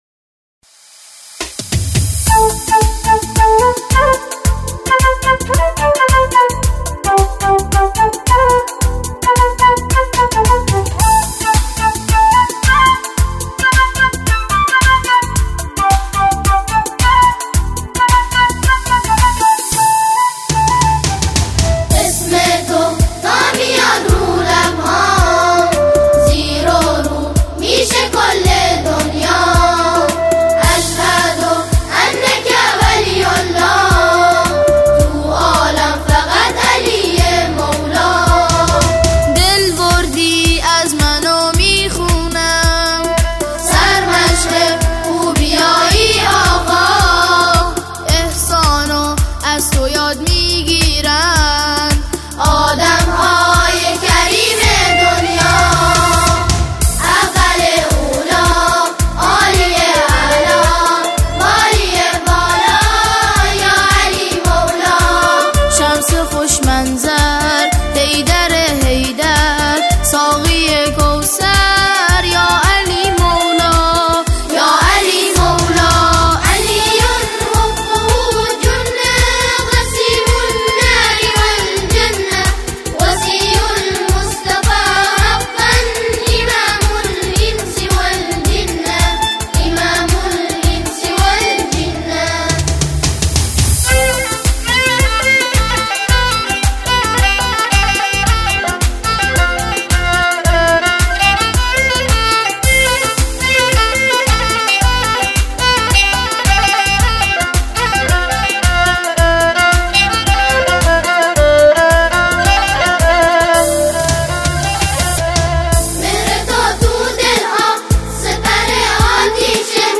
به صورت جمع خوانی